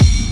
kits/Southside/Kicks/Southside SK (18).wav at main